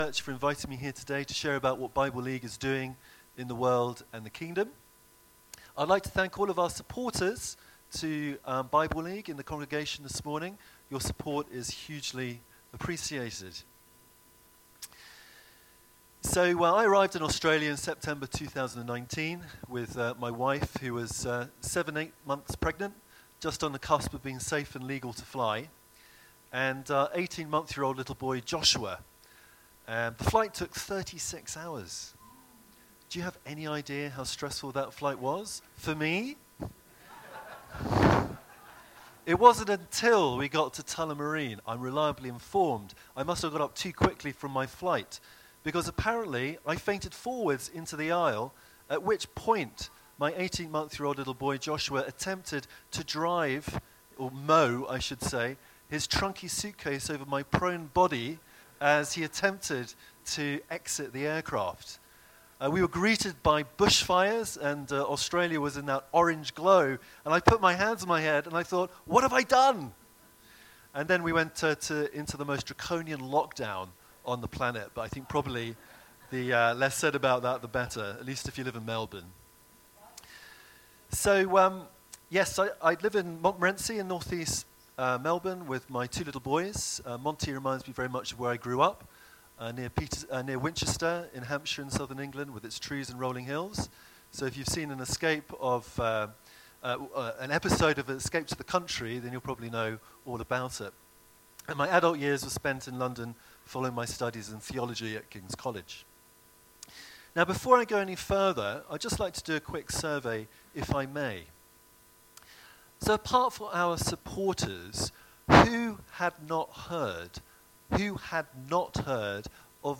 2025 • 30.98 MB Listen to Sermon Download this Sermon Download this Sermon To download this sermon